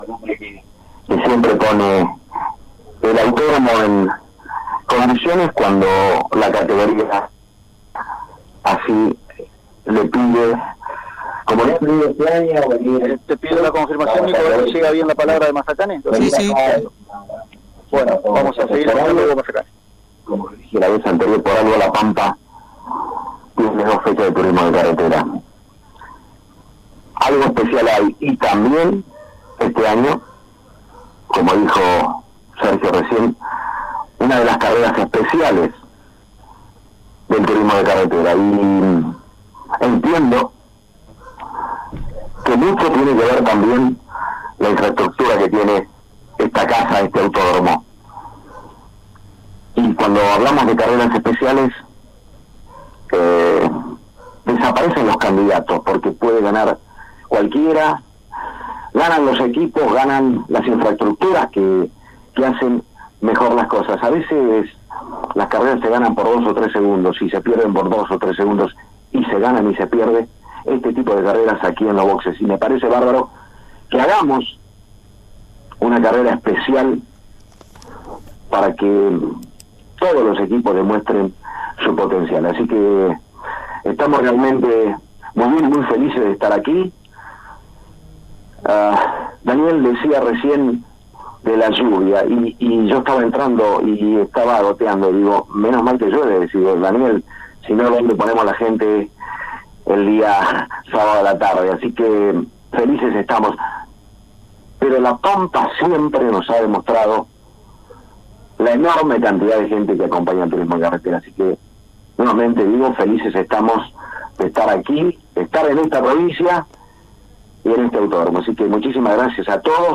El presidente de la categoría habló en conferencia de prensa en Toay, donde quedó presentada la competencia especial del fin de semana.